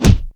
PUNCH  1.WAV